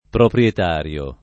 proprietario
[ propr L et # r L o ]